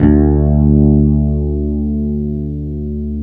Index of /90_sSampleCDs/Roland LCDP02 Guitar and Bass/BS _E.Bass 5/BS _Dark Basses